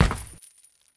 SoldierSoundsPack
fall.wav